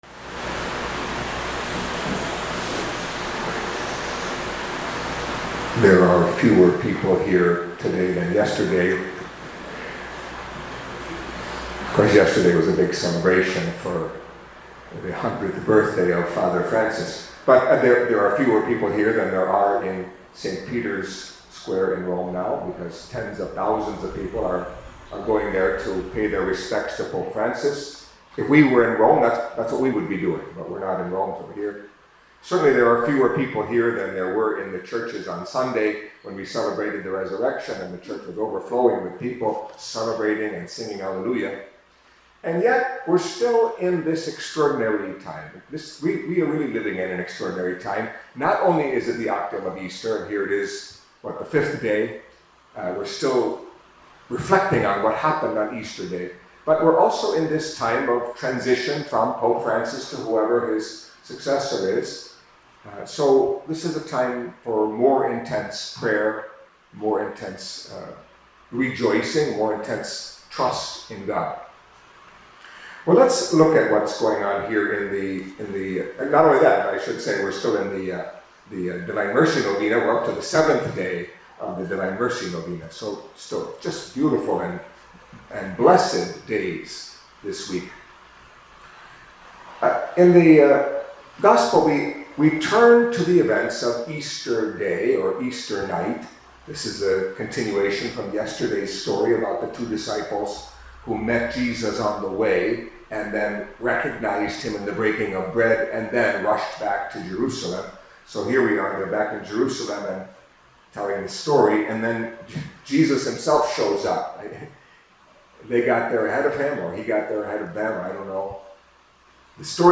Catholic Mass homily for Thursday in the Octave of Easter